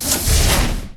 doorsclosed.ogg